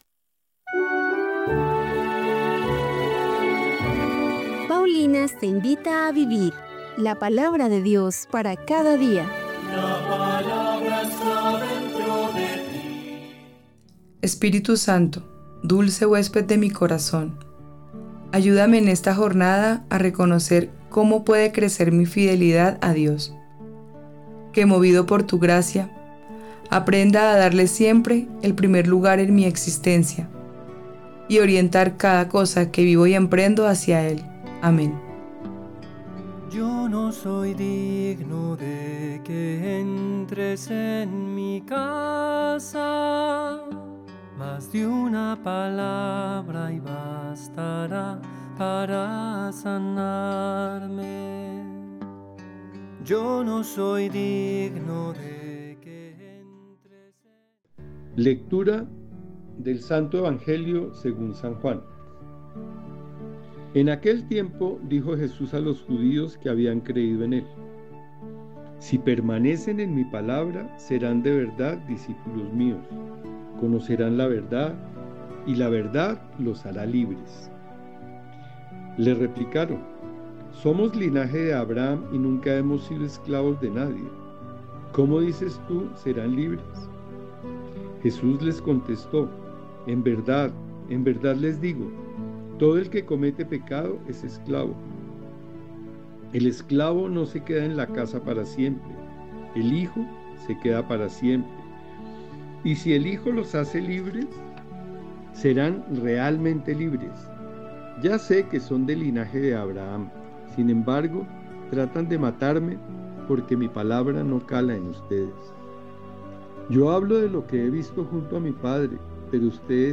Lectura de los Hechos de los Apóstoles 4, 32-37